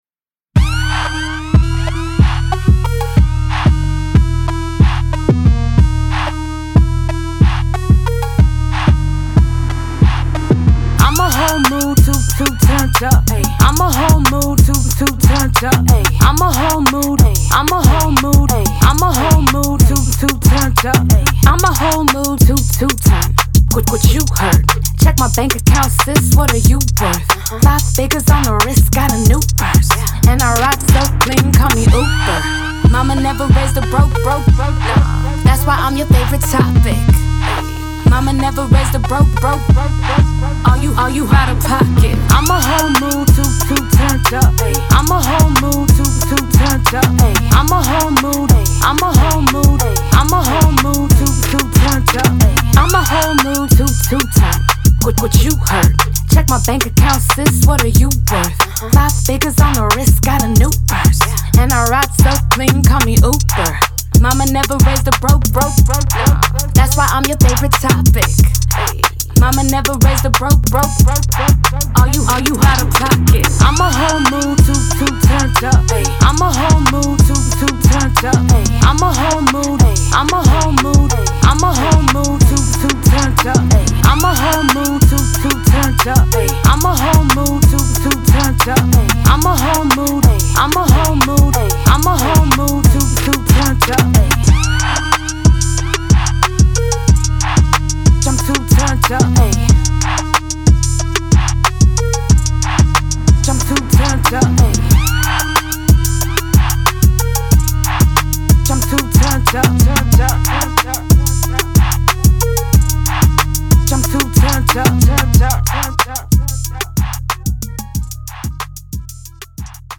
Hip Hop
E min